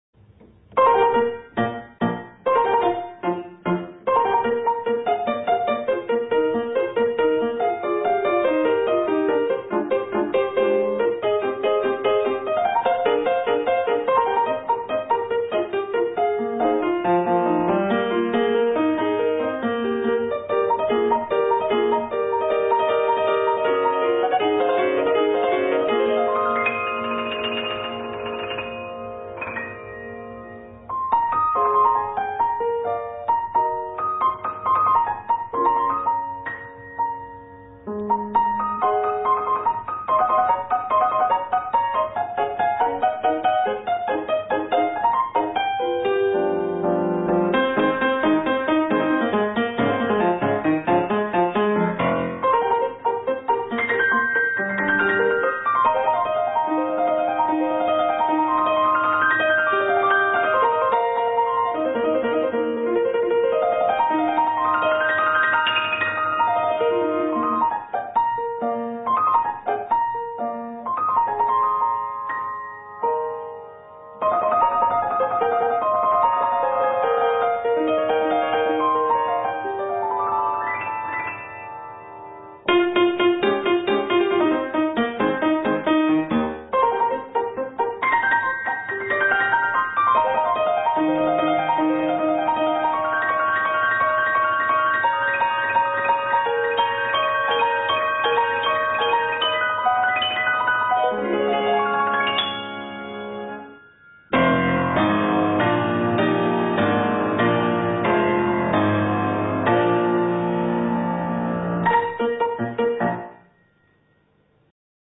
作曲：嚴老烈     演奏：鋼琴
樂曲有不少樂句是七聲音階的旋律。
現今用鋼琴演奏，亦有近似效果。